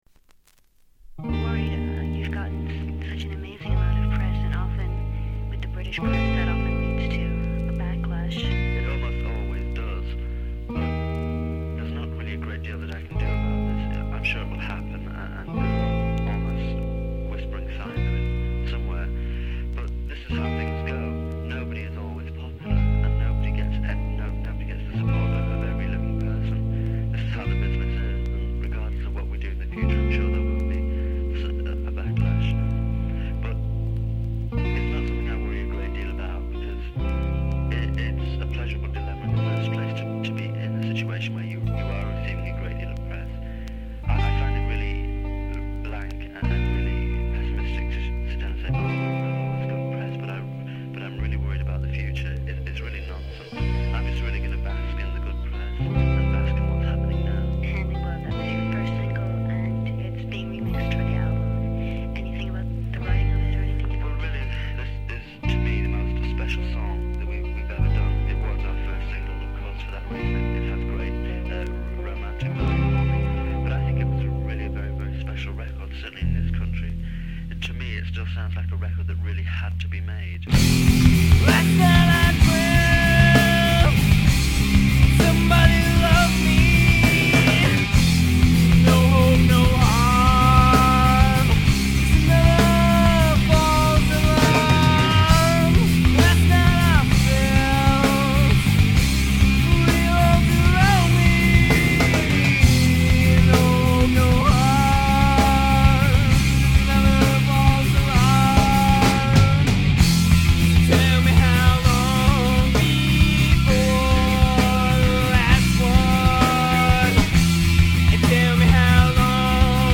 Vocals/Bass
Guitar
Drums
Emo